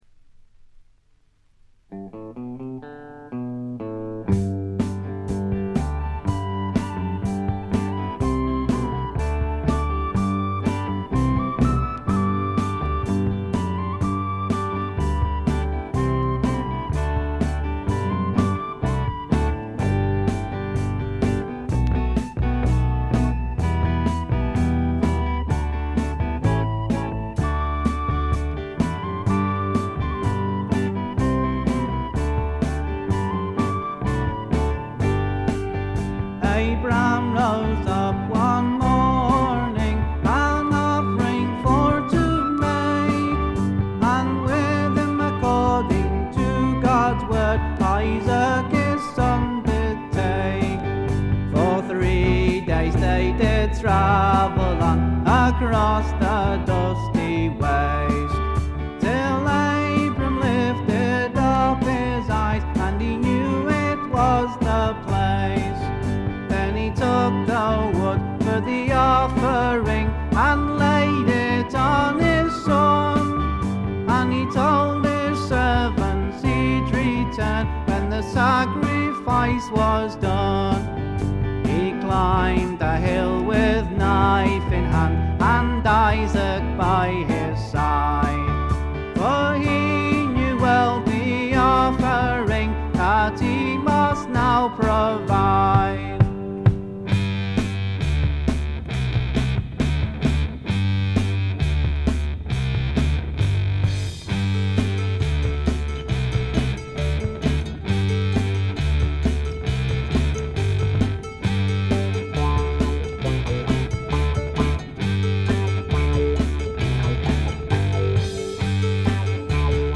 見た目に反してプレスがいまいちのようで、ところどころでチリプチ。プツ音少々。
リヴァプールの男性4人組フォークバンドによるメジャー級の素晴らしい完成度を誇る傑作です。
格調高いフォークロックの名盤。
試聴曲は現品からの取り込み音源です。
Recorded At - Canon Sound Studio, Chester